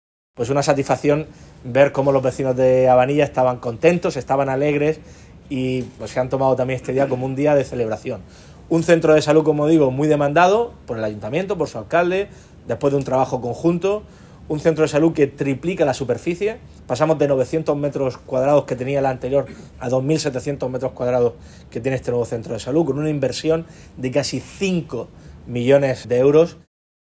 Declaraciones del presidente del Gobierno de la Región de Murcia, Fernando López Miras, sobre el Centro de Salud de Abanilla inaugurado hoy.